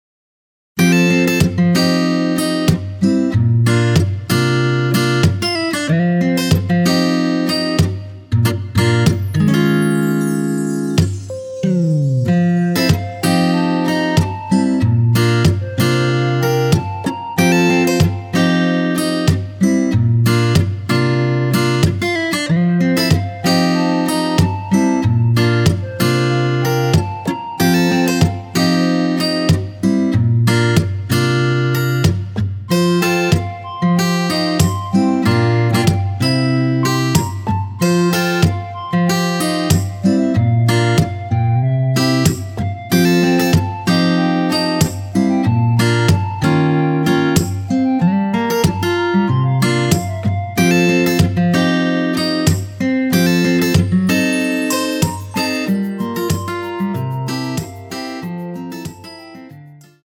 원키에서(+4)올린 멜로디 포함된 MR입니다.
앞부분30초, 뒷부분30초씩 편집해서 올려 드리고 있습니다.
중간에 음이 끈어지고 다시 나오는 이유는